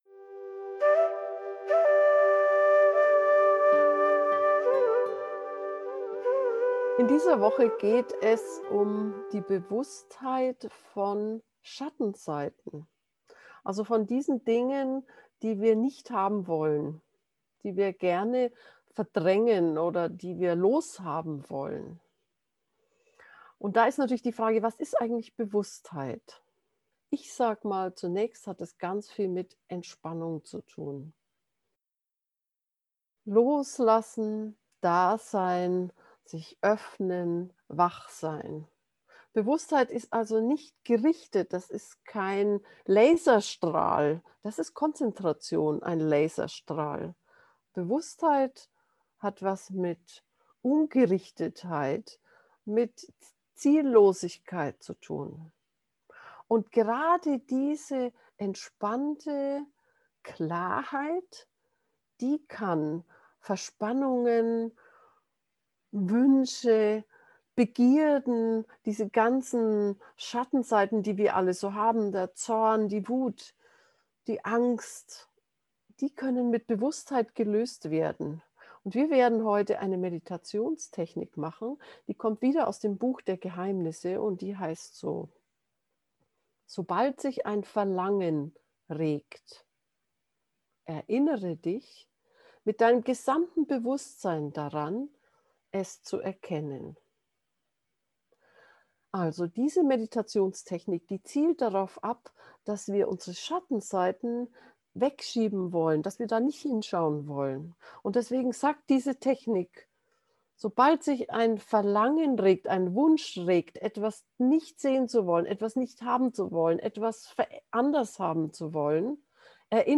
Mit dieser geführten Meditation bekommst du einen Geschmack über den Segen von Bewusstheit.
segen-bewusstheit-gefuehrte-meditation.mp3